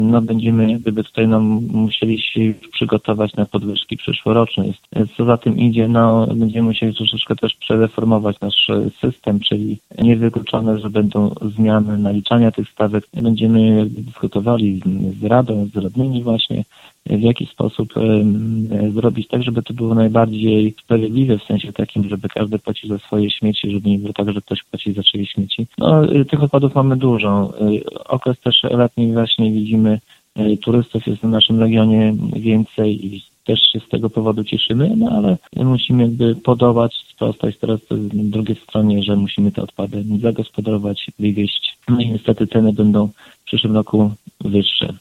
– mówi Zbigniew Mackiewicz, wójt gminy Suwałki